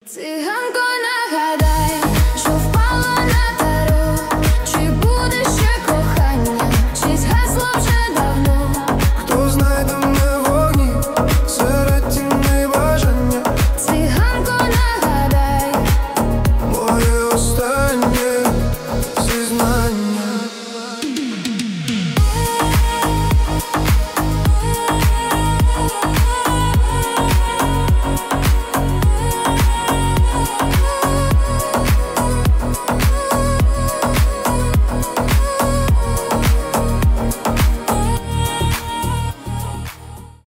танцевальные , ремиксы